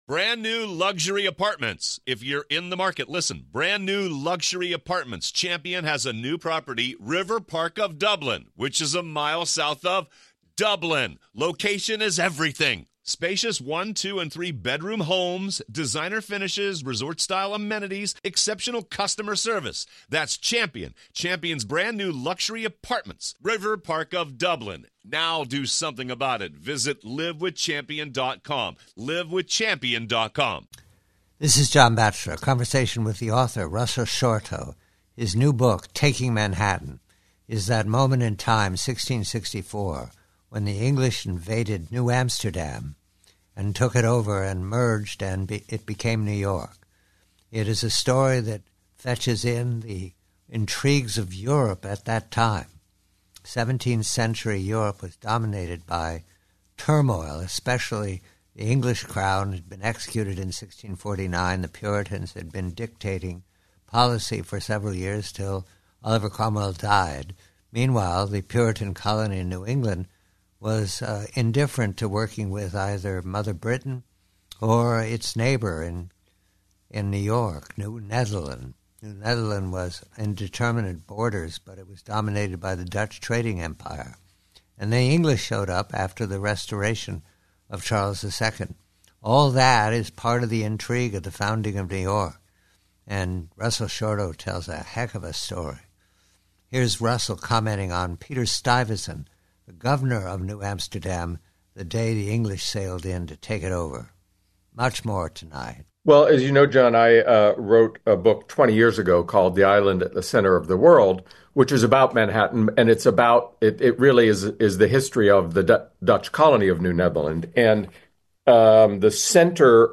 Preview: Conversation with Russell Shorto, author "Taking Manhattan," re the character of the Governor of New Netherland, Peter Stuyvesant, when the English invaded to conquer in 1664.